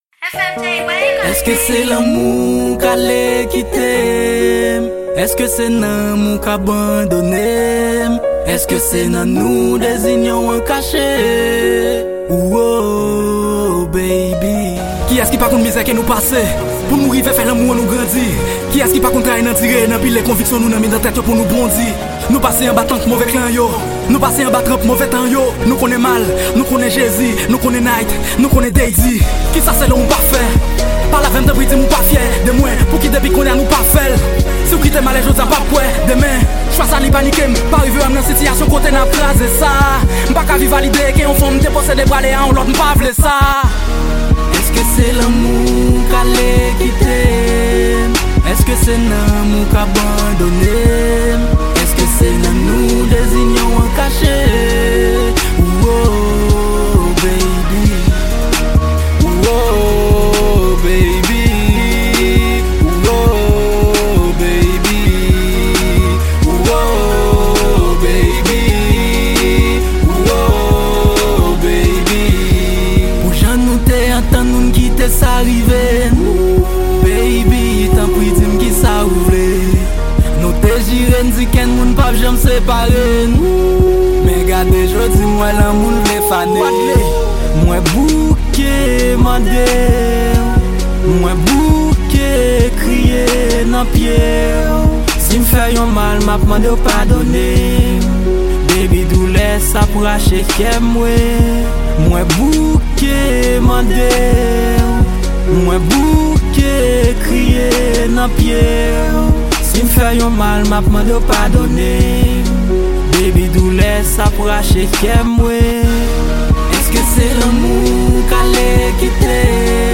Genre: RAP & RNB.